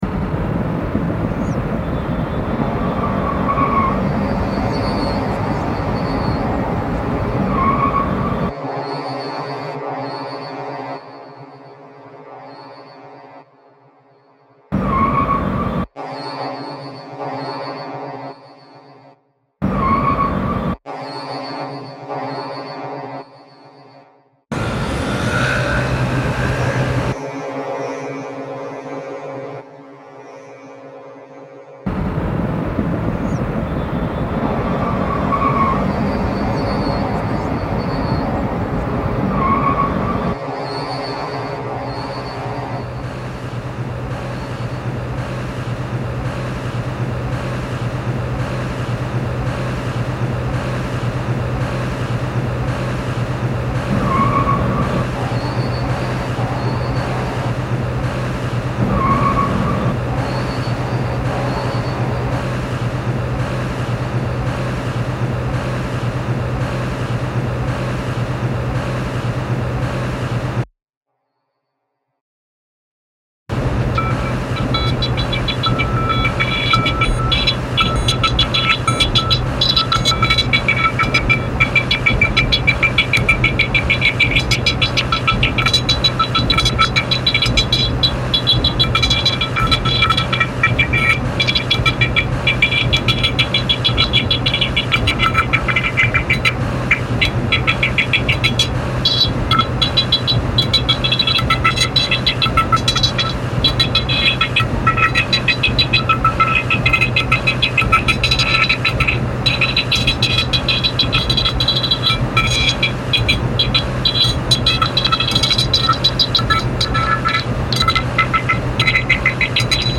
Medellin soundscape reimagined